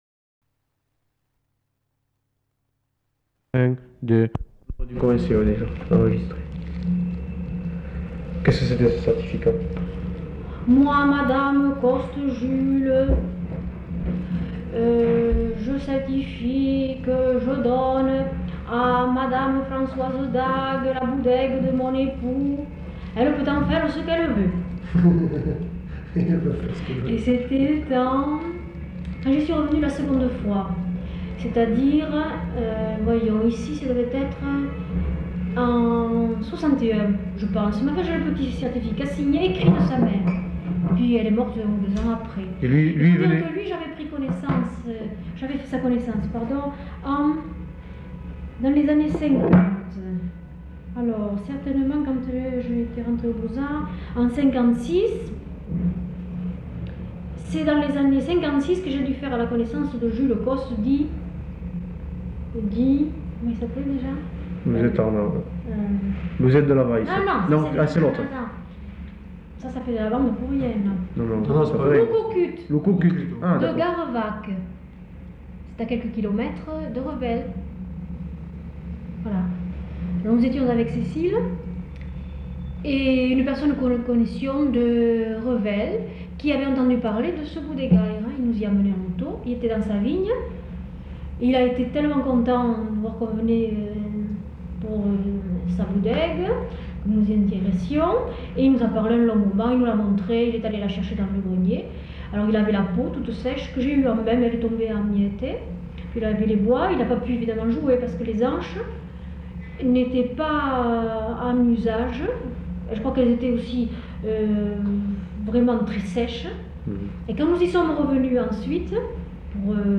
Lieu : Toulouse
Genre : récit de vie